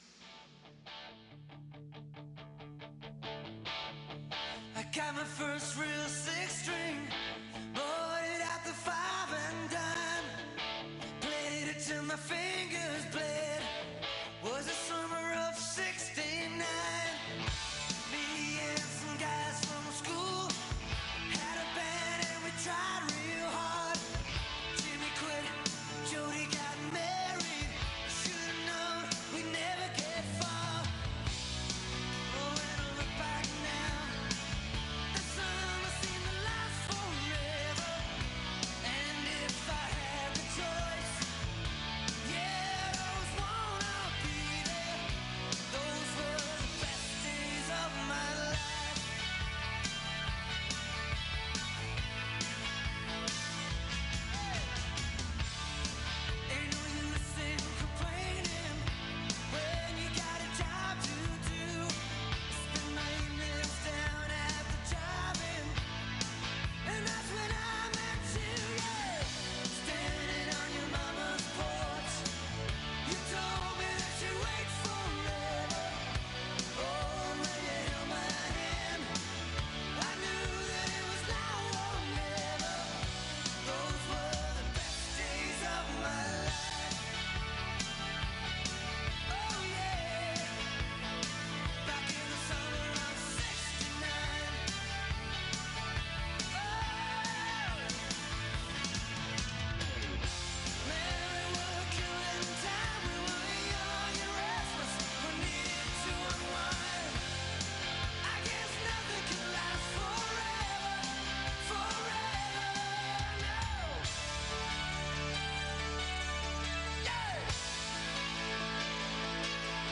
Ich durfte den Sprint der Männer am 15. Februar und die beiden Staffelwettkämpfe am 22. Februar live für euch mitverfolgen und die Stimmen unserer besten Biathletinnen und Biathleten einfangen.